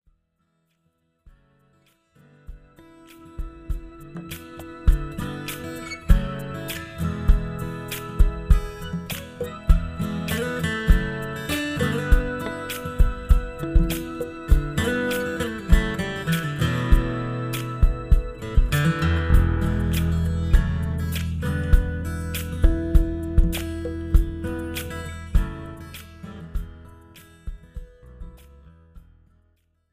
Blues , Rock